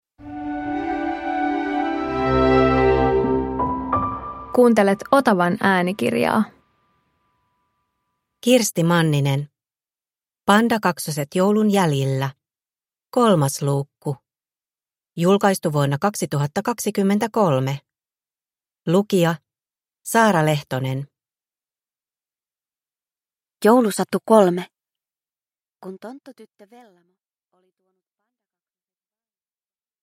Pandakaksoset joulun jäljillä 3 – Ljudbok